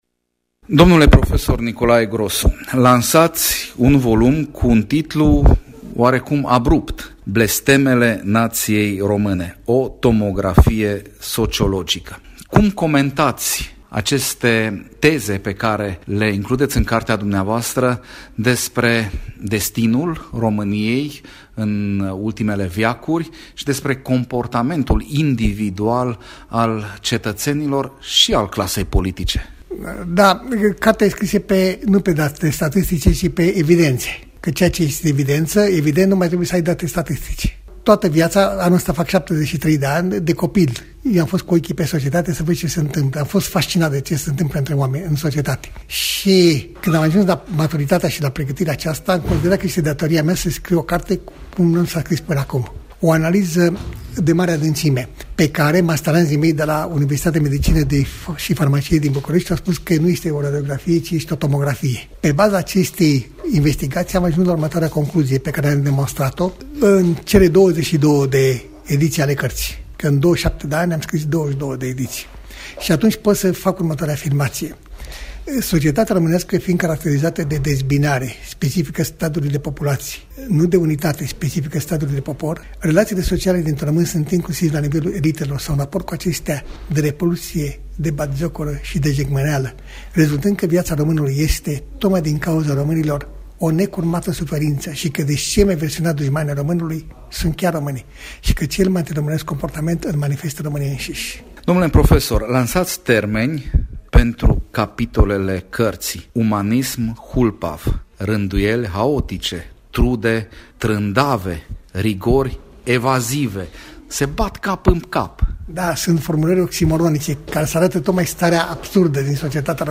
interviul